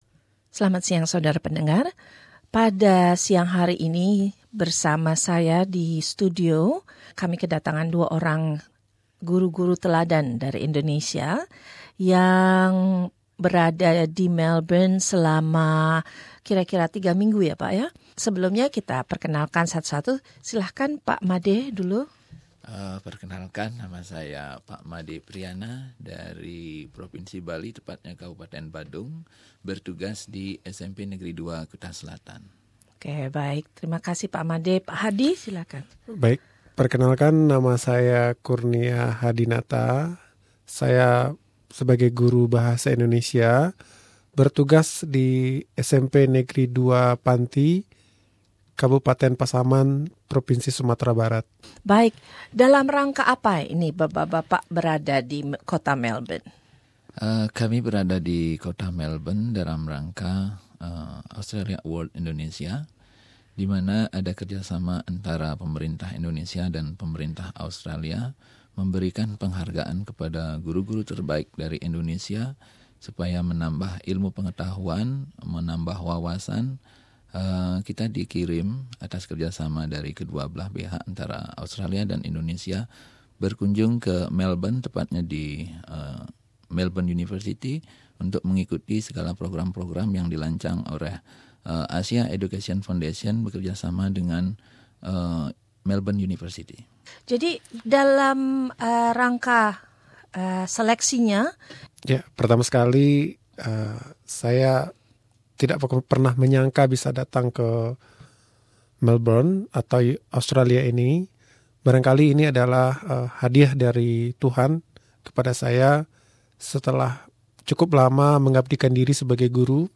Bincang-bincang dengan para Guru Teladan